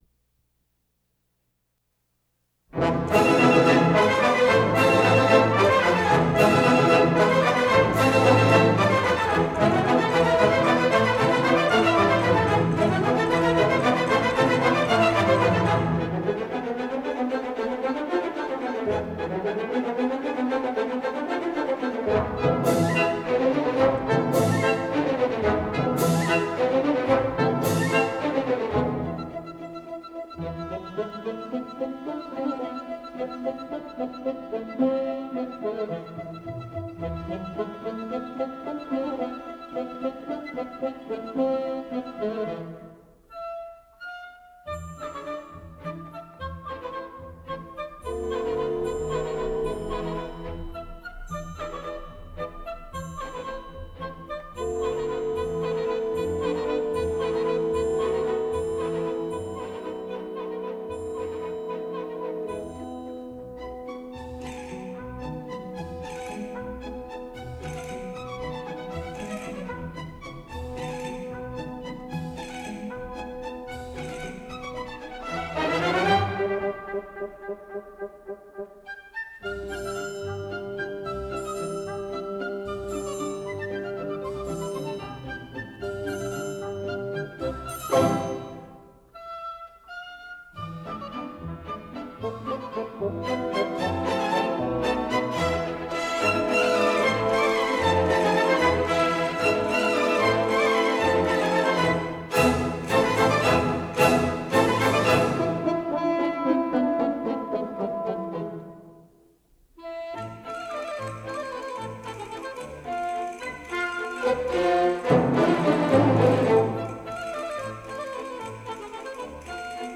Recording Info: Transferred from a London 4-track tape